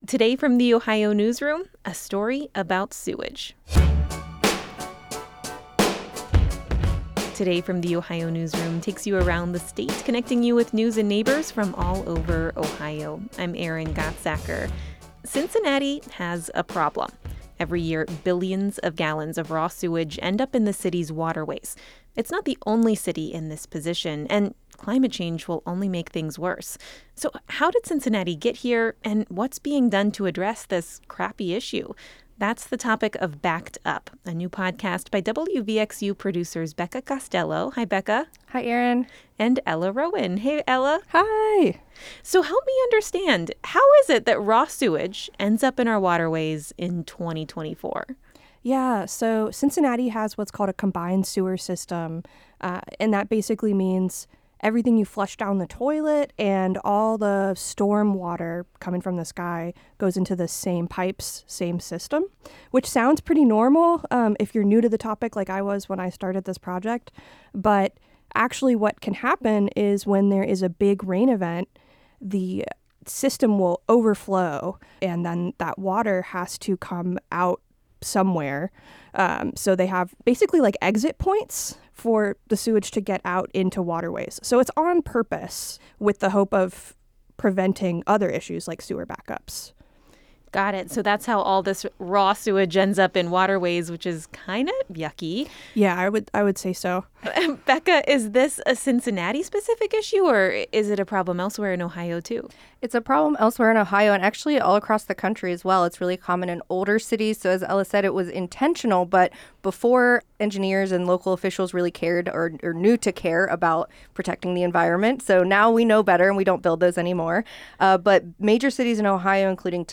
This conversation has been lightly edited for clarity and brevity.